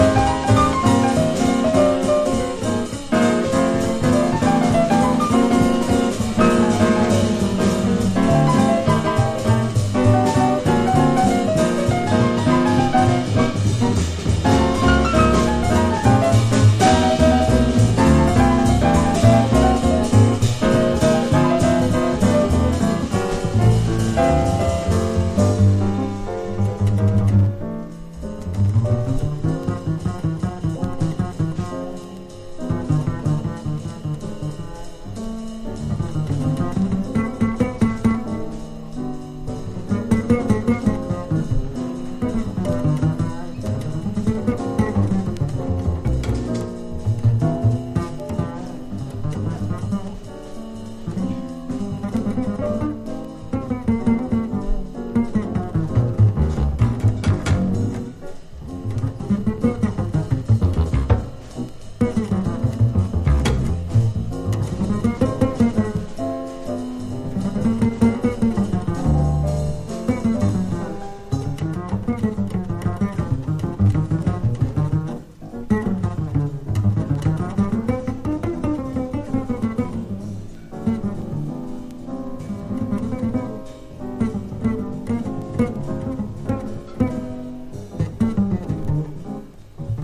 1970年モントルー・ジャズフェスティバルでの実況録音盤。